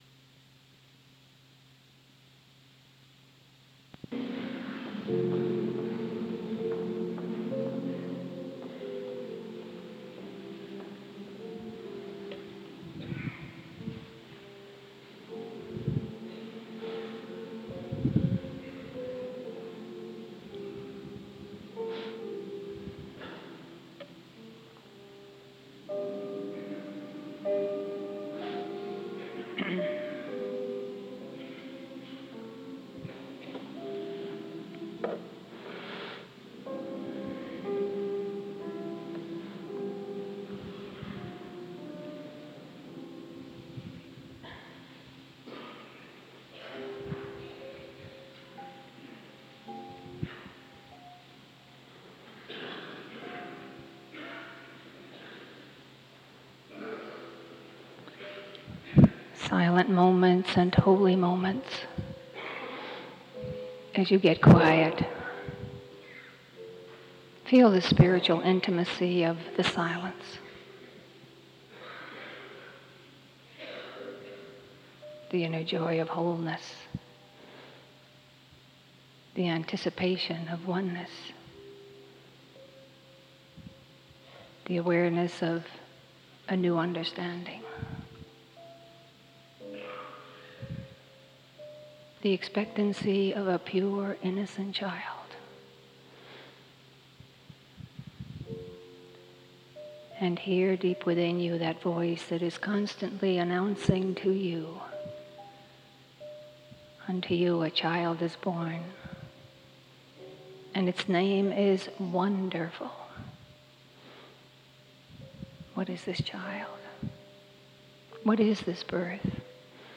Sunday Services